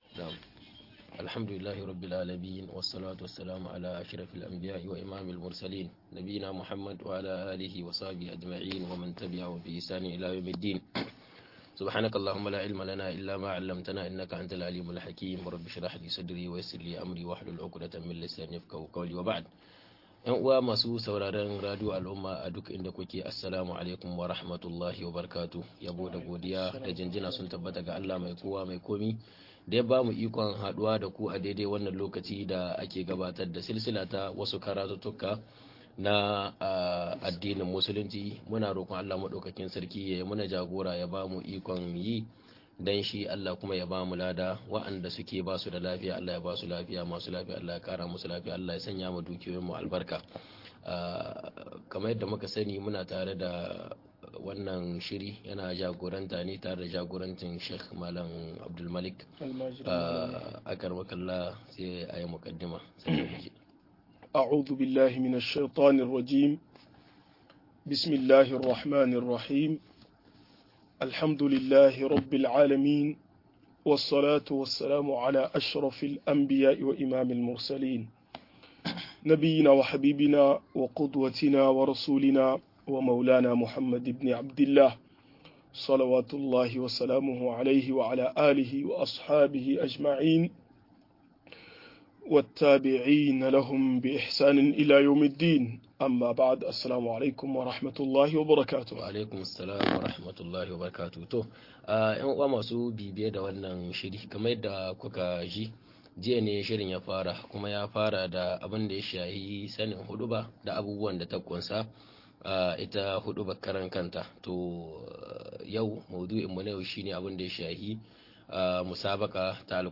Bayani kan musabaqa-01 - MUHADARA